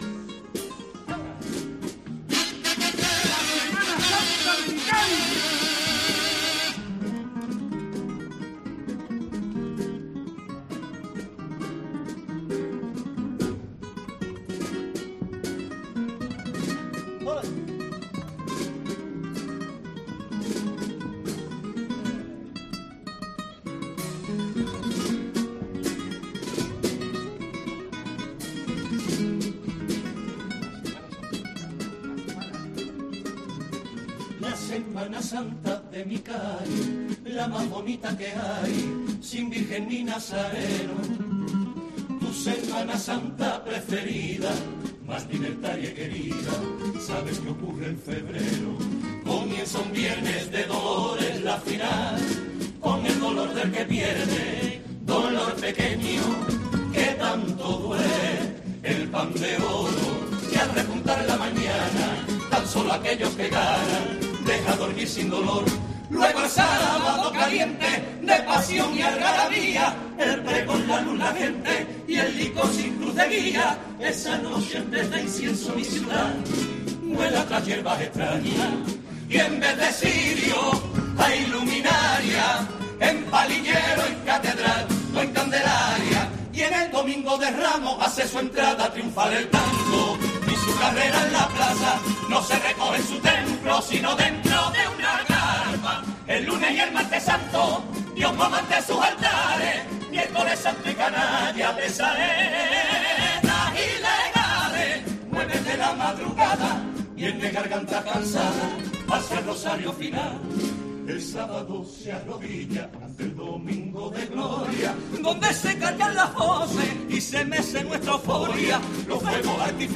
El pasodoble de Los esclavos que levantó el Falla en semifinales